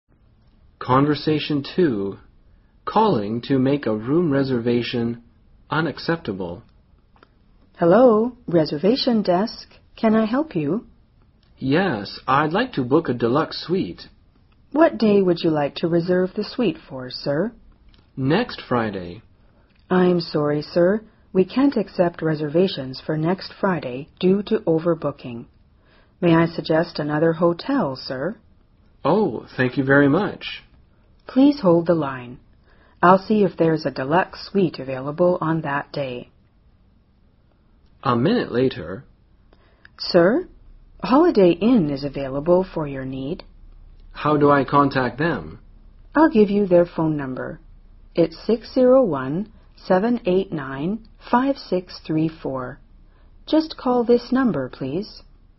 【对话2：打电话预约房间（无空房）】